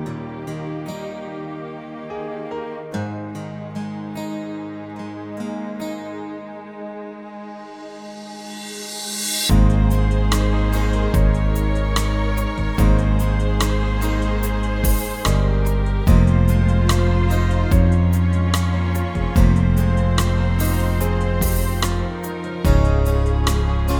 no Backing Vocals Pop (2000s) 3:37 Buy £1.50